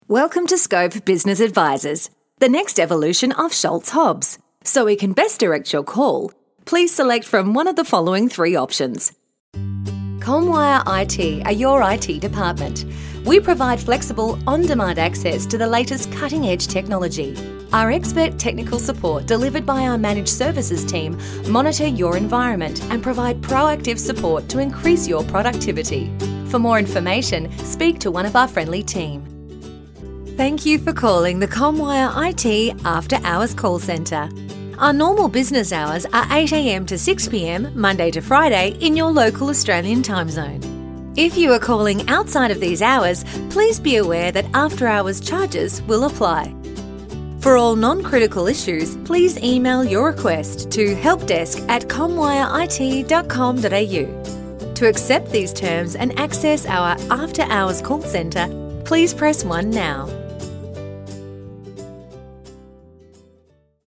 Engels (Australië)
Commercieel, Speels, Veelzijdig
Corporate